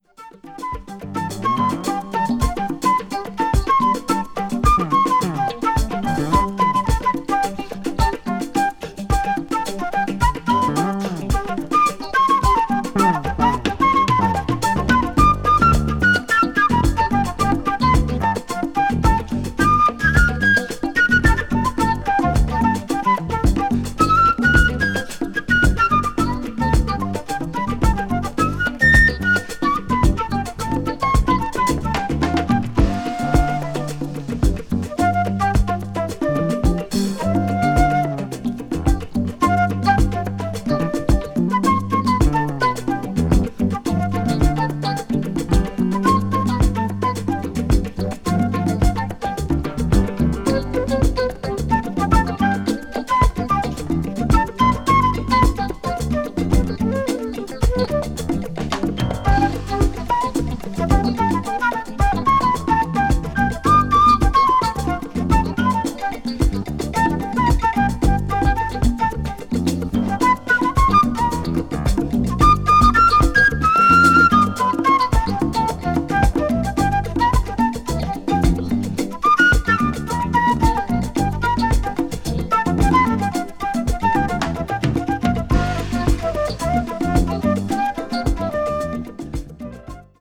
media : EX/EX(わずかにチリノイズが入る箇所あり)